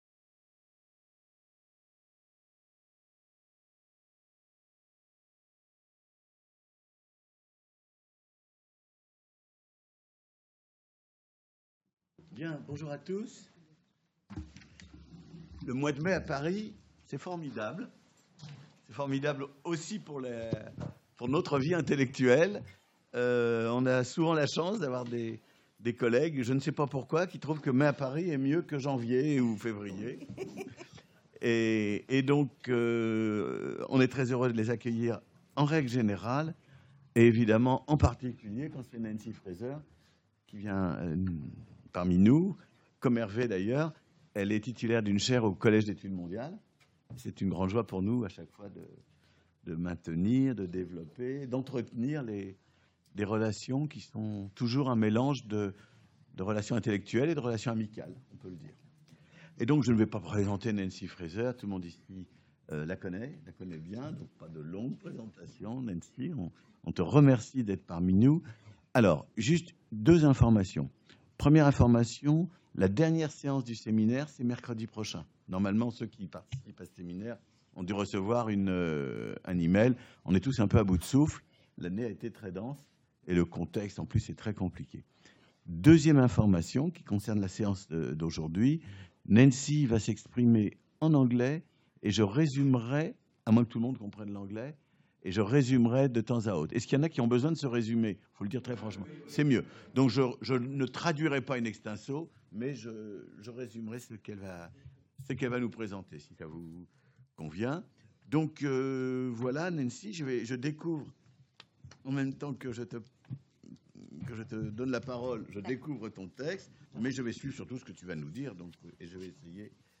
Sociologie du conflit avec Nancy Fraser - Mercredi 23 mai Séance du séminaire Sociologie du conflit de Michel Wieviorka et Hervé Le Bras avec comme invitée Nancy Fraser, titulaire de la chaire Repenser la justice sociale. Séance en anglais, traduite en français.